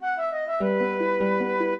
flute-harp
minuet9-4.wav